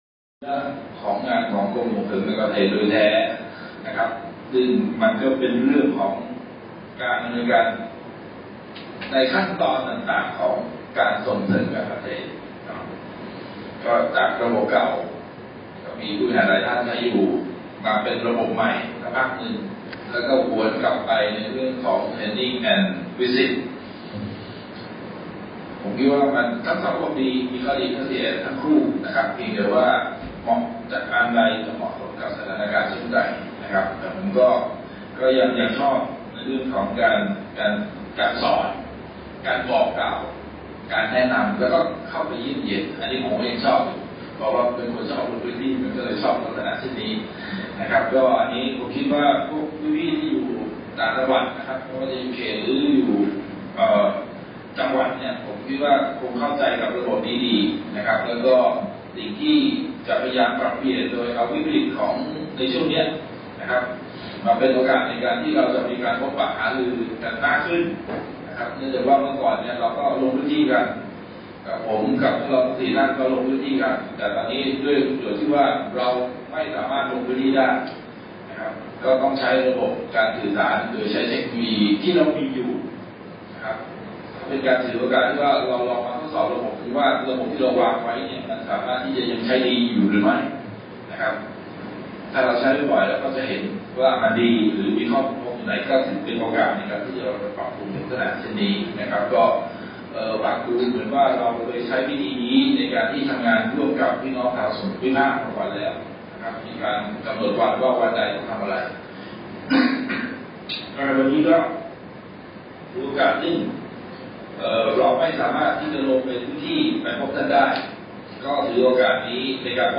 แนวทางการดำเนินงานส่งเสริมการเกษตรในพื้นที่ตามระบบส่งเสริมการเกษตร T&V System จากการประชุมทางไกล (Web Conference)